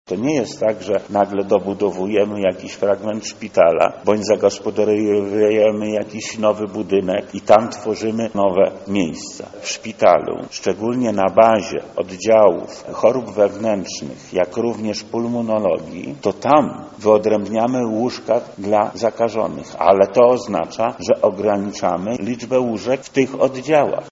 Warto jednak pamiętać, że zwiększenie łóżek dla zakażonych, to mniej miejsc dla innych pacjentów. Dlatego podjęliśmy metodę wolnych kroków – mówi wojewoda lubelski Lech Sprawka: